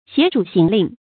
挟主行令 jiā zhǔ xíng lìng
挟主行令发音